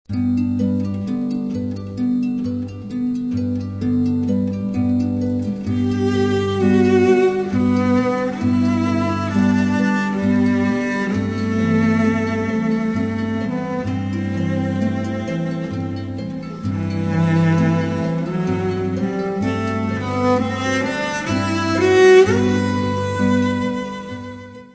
harp
(all with cello except where indicated)